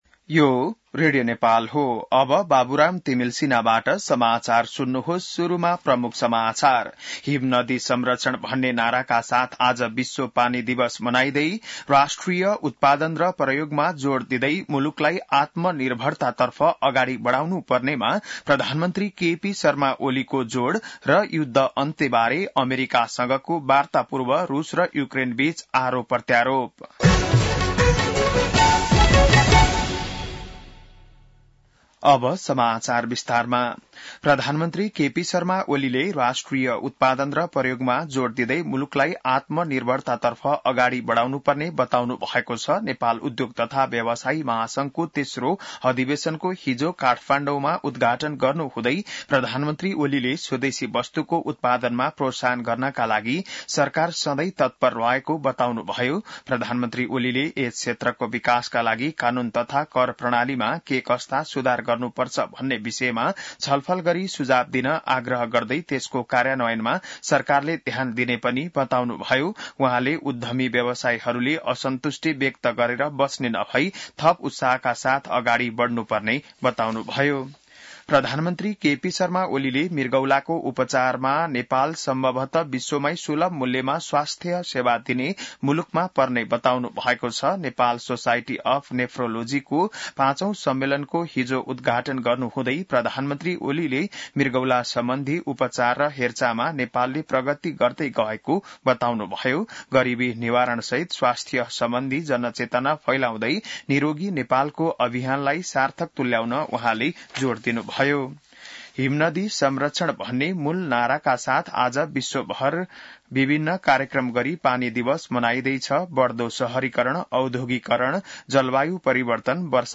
बिहान ९ बजेको नेपाली समाचार : ९ चैत , २०८१